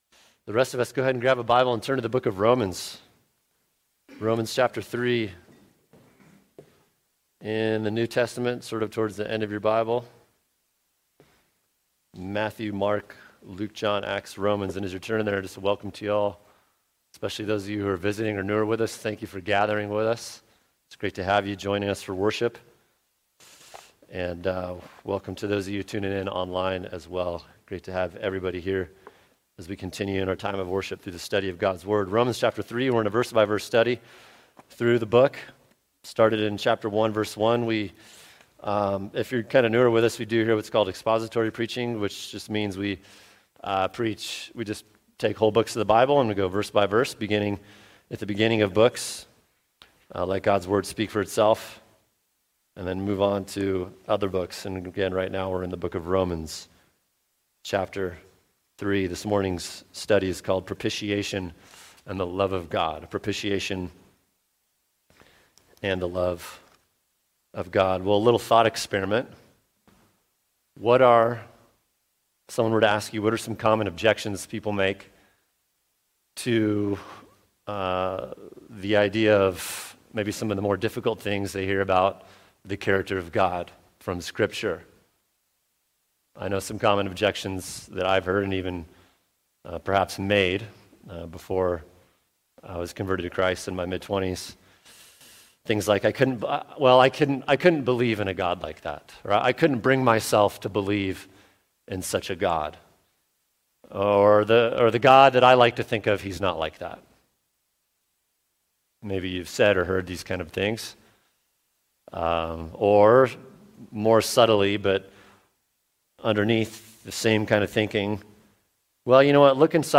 [sermon] Romans 3:25a Propitiation & The Love of God | Cornerstone Church - Jackson Hole